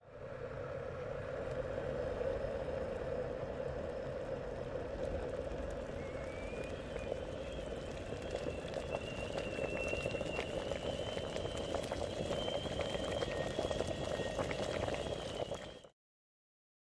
水壶烧开的水
描述：水煮沸在电热水壶。添加哨子给它一个老派的锅感觉。 放大H6录音机
Tag: 厨房 哨子 水壶 炉子 OWI